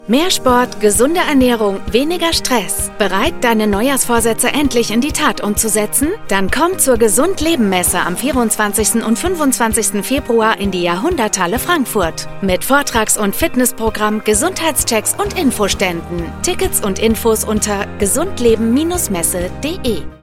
Accurate
Charming
Clear